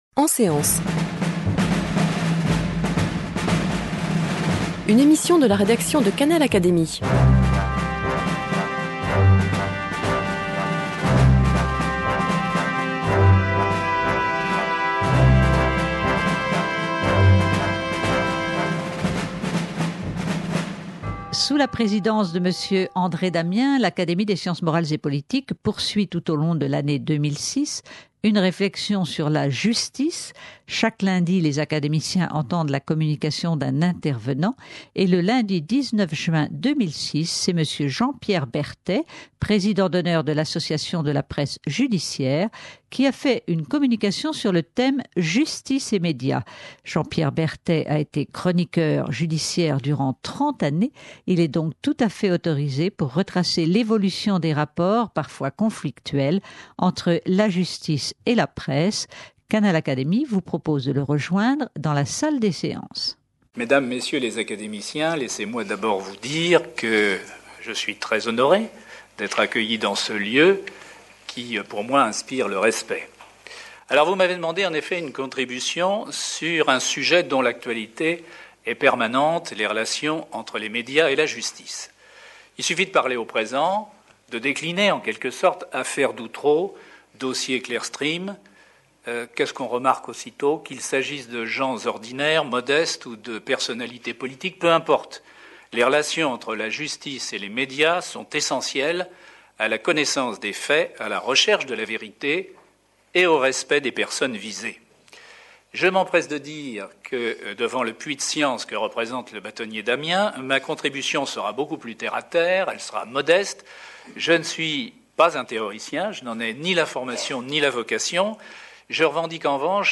Chaque lundi, les académiciens réunis en séance entendent la communication d'un intervenant sur ce thème.